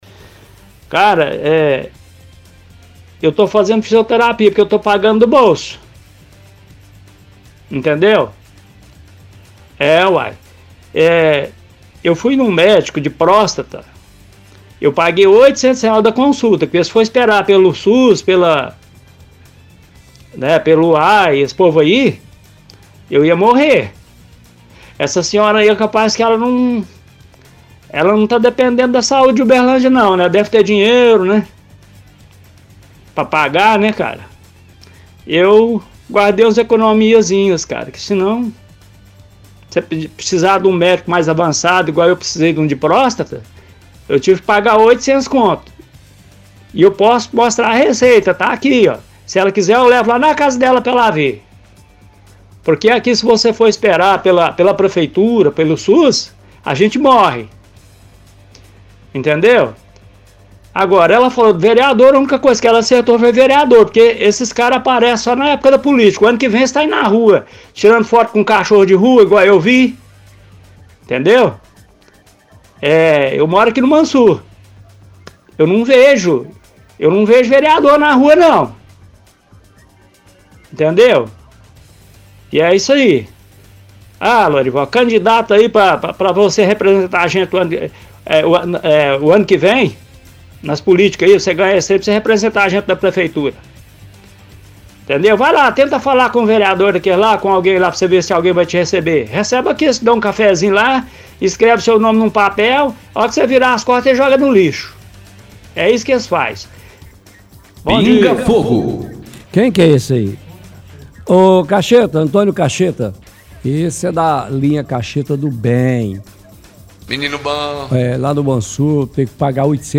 Home / Rádio / Pinga fogo – SUS